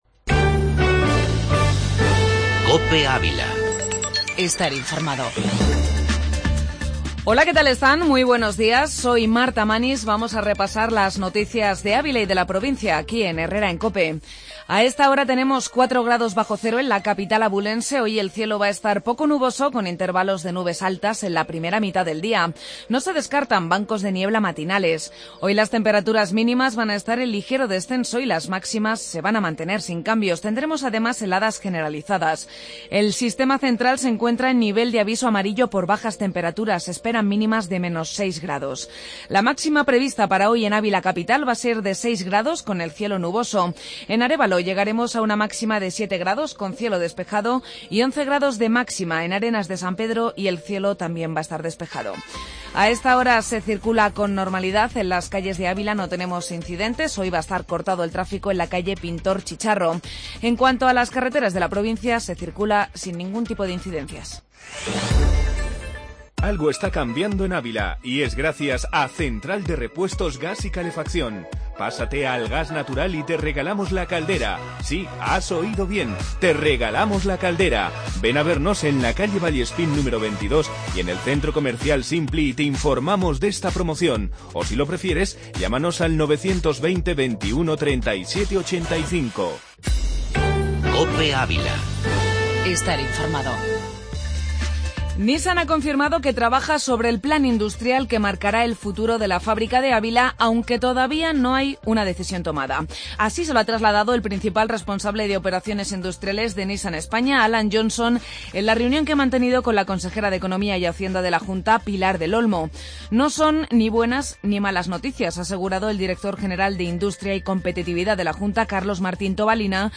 AUDIO: Informativo local y provincial.